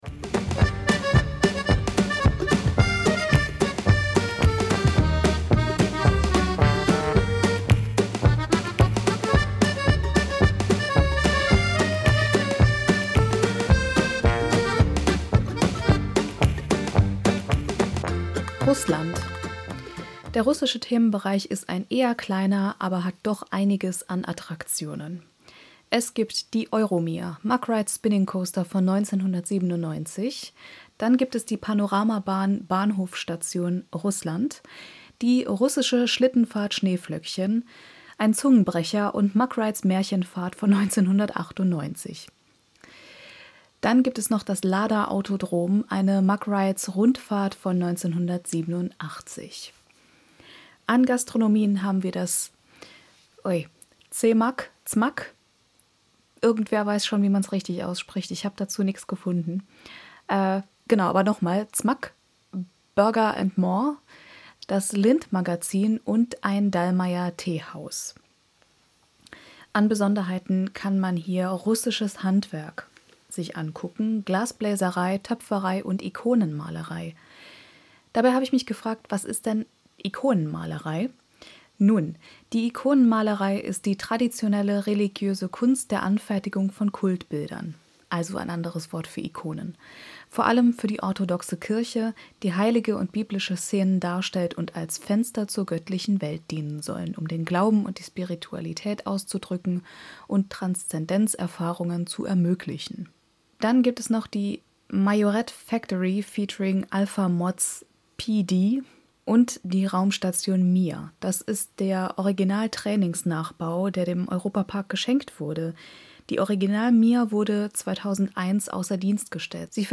Music by Tunetank from Pixabay